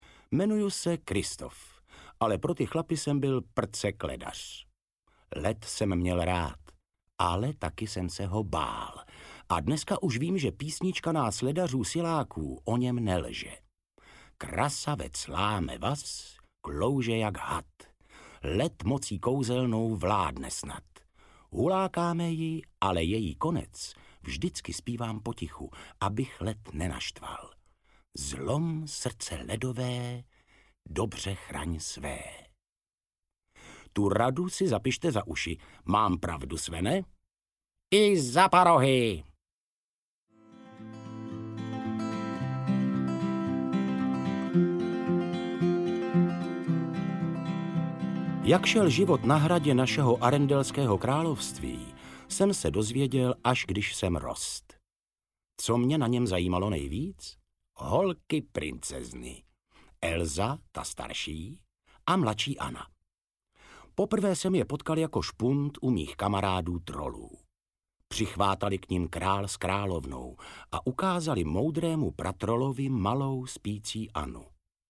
Audiopohádky podle filmů Disney.